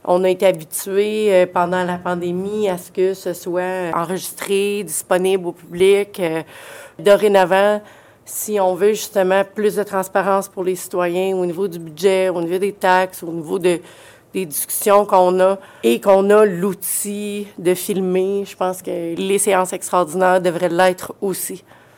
La conseillère du district de la Citière, Marie Ève Plante-Hébert, reconnaît que le traitement de la séance du 22 décembre a été fautif. Elle a profité de son intervention pour faire valoir l’importance d’enregistrer toutes les séances du conseil.